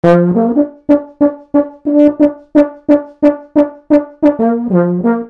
Tuba.mp3